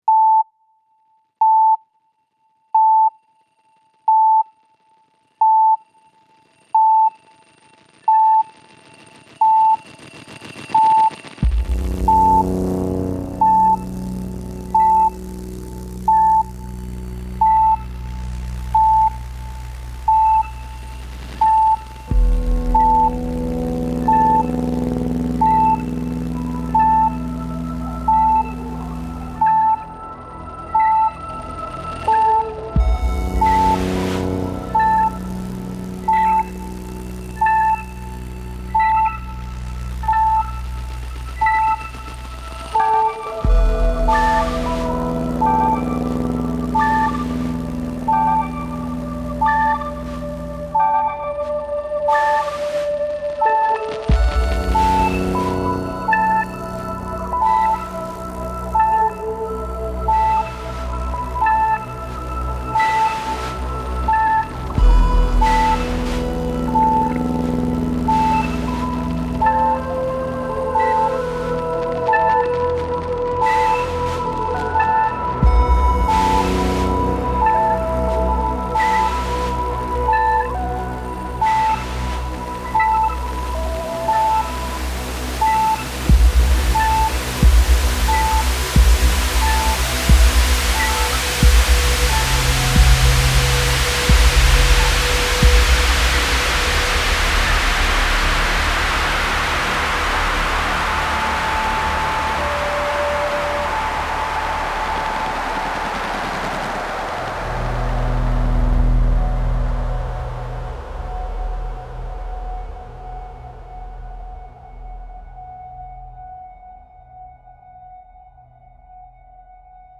– les temps impairs se comptent sur ces « bips » !